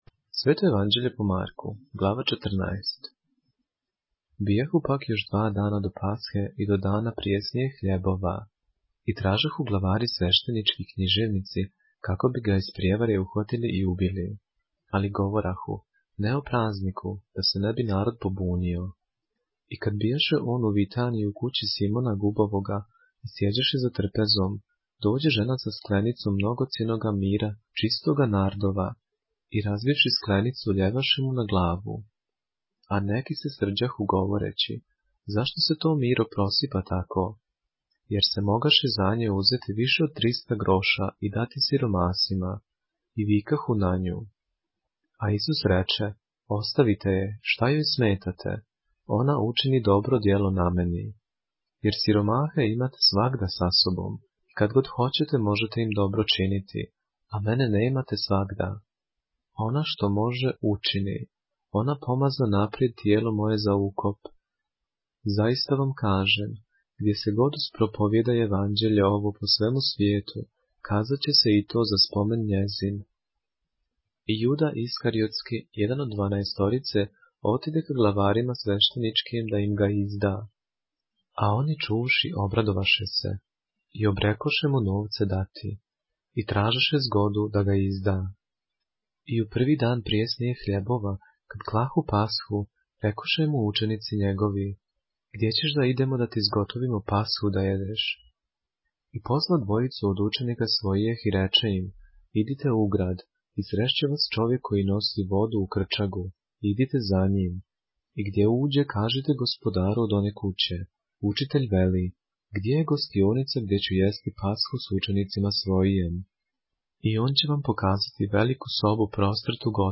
поглавље српске Библије - са аудио нарације - Mark, chapter 14 of the Holy Bible in the Serbian language